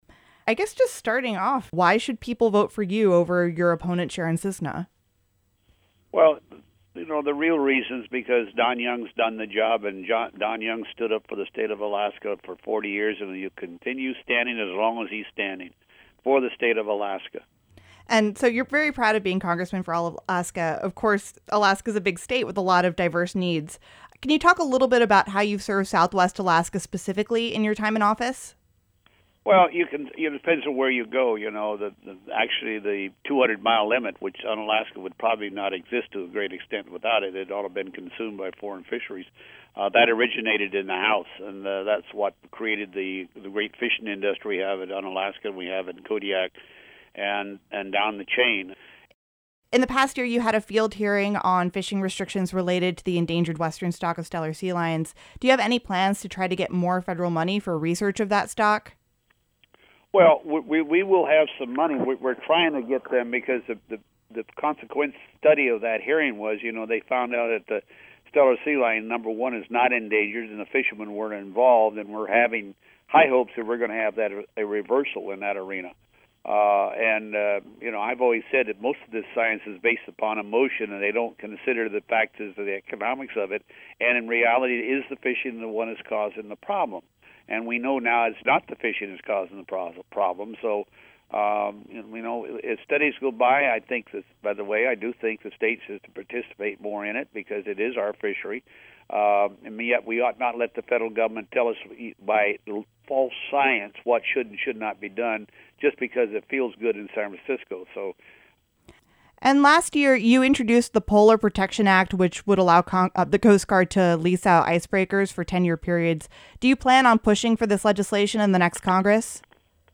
This week, KUCB is airing interviews with candidates for Alaska’s one congressional seat and with the region’s representatives in the State Legislature who are up for reelection.